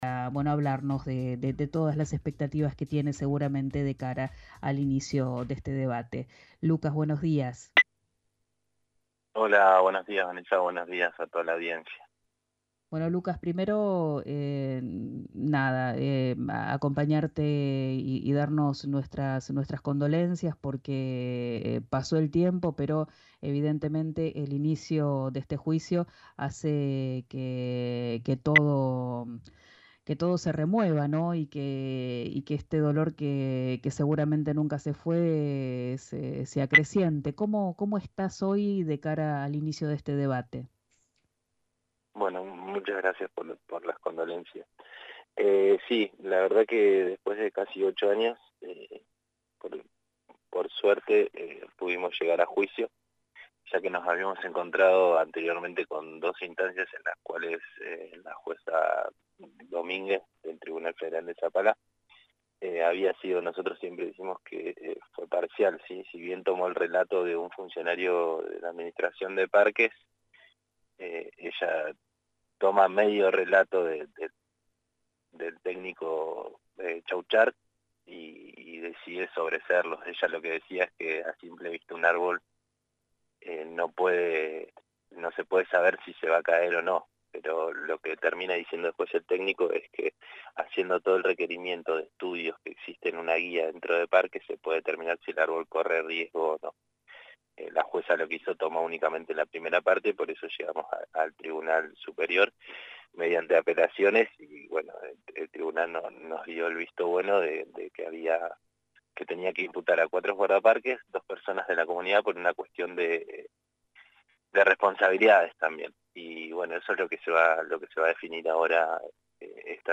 en comunicación con «Arranquemos», por RÍO NEGRO RADIO